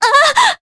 Juno-Vox_Damage_jp_02.wav